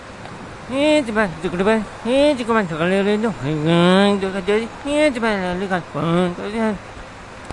Tag: 中环站 Textur E 吉隆坡 吉隆坡